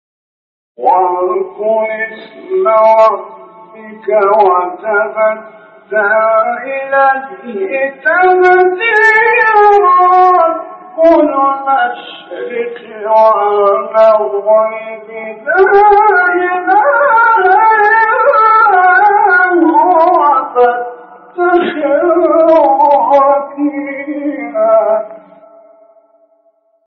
سوره : مزمل آیه: 8-9 استاد : محمد رفعت مقام : سه گاه قبلی بعدی